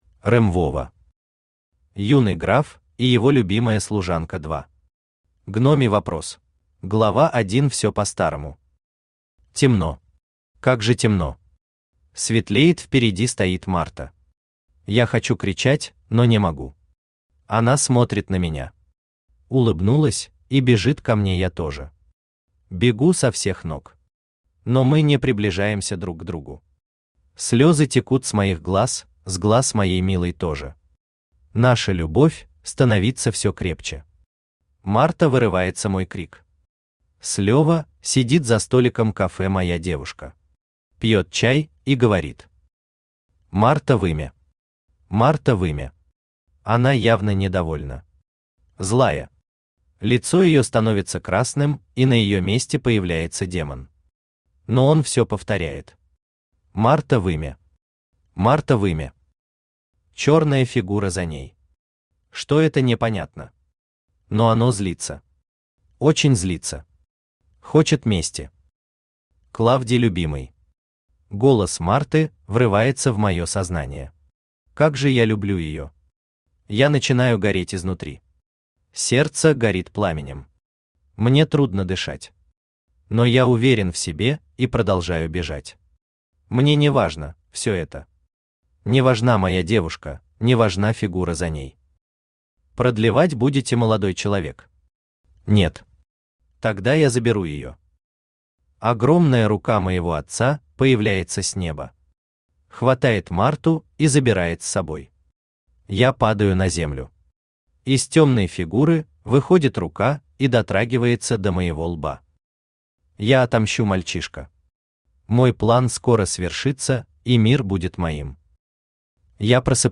Аудиокнига Юный граф и его любимая служанка 2. Гномий вопрос | Библиотека аудиокниг